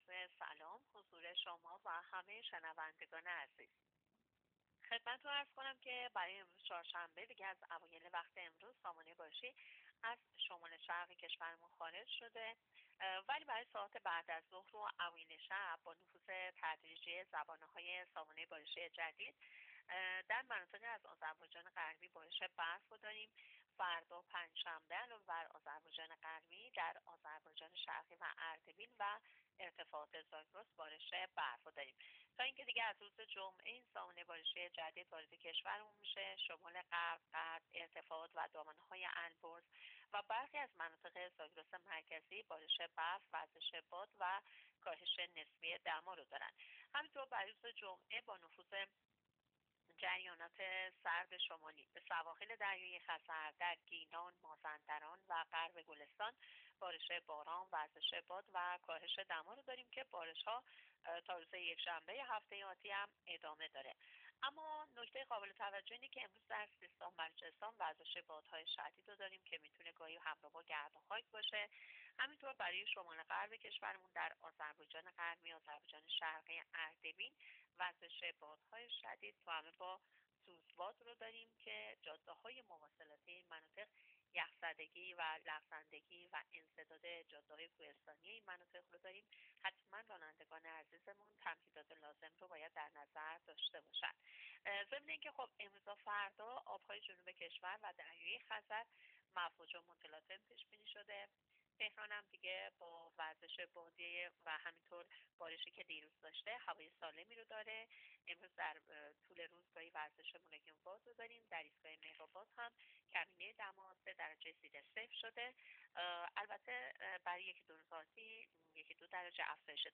گزارش رادیو اینترنتی از آخرین وضعیت آب و هوای دهم دی؛